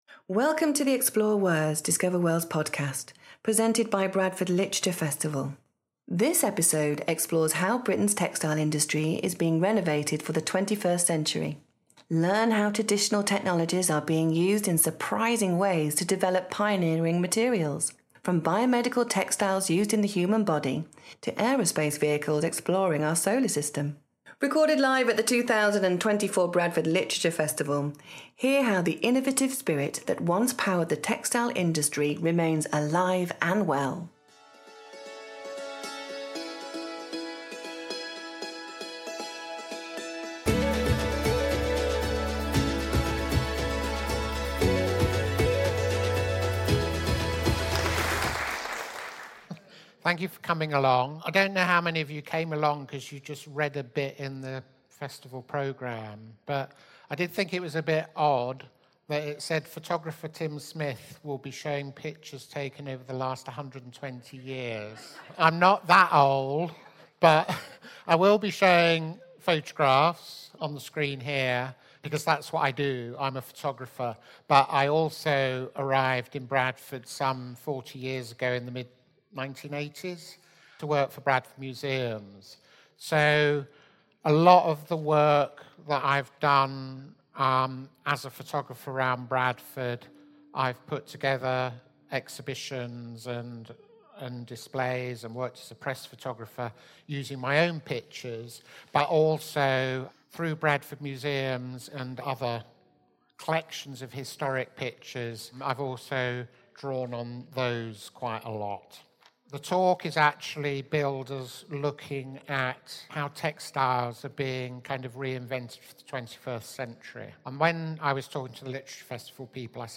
In this heritage talk, you will explore how Britain’s textile industry is being reinvented for the 21st century.